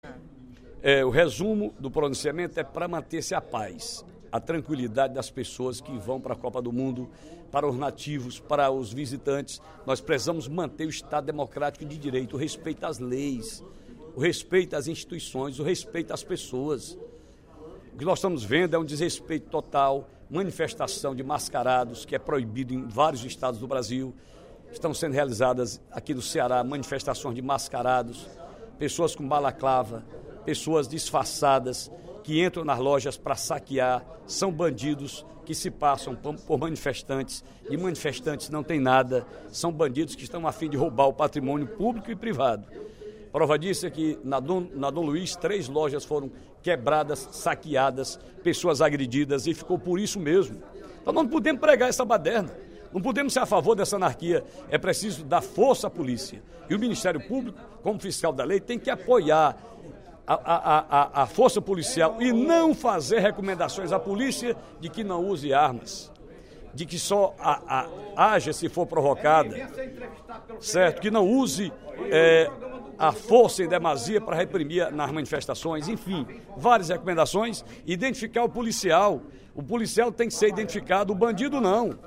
No primeiro expediente da sessão plenária desta terça-feira (10/06), o deputado Ferreira Aragão (PDT) cobrou do Ministério Público medidas mais severas para respaldar a ação da Polícia durante as manifestações.